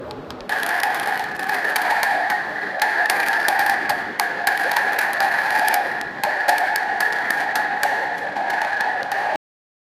MOROCCO GNAWA
morocco-gnawa--mufpyeos.wav